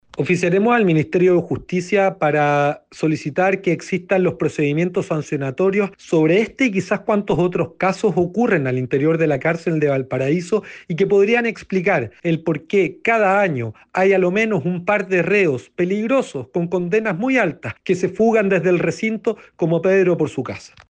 Por otro lado, desde el oficialismo, el diputado frenteamplista Jorge Brito, indicó que oficiará al Ministerio de Justicia y Derechos Humanos, con el fin de que entreguen todos los antecedentes que den cuenta de este hecho, para dar con el respectivo procedimiento sancionatorio.